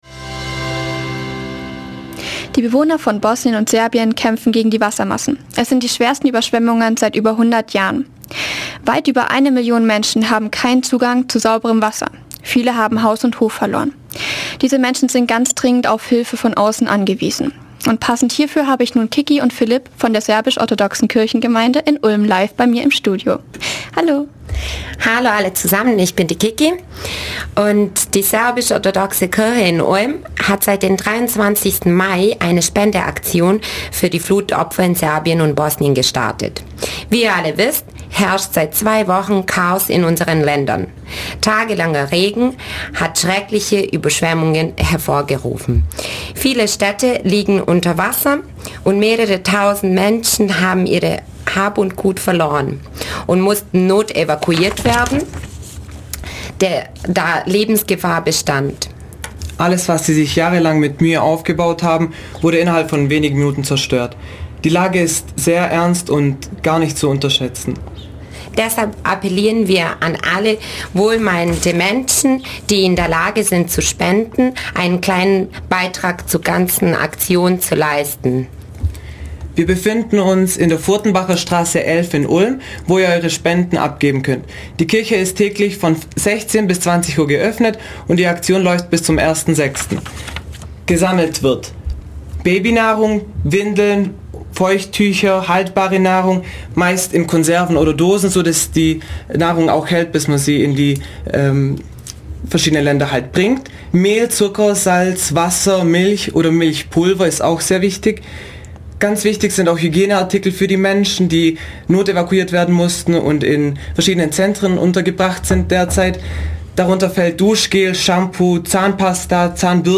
Radio
interview_spendenaktion_fuer_flutopfer_in_bosnienserbien.mp3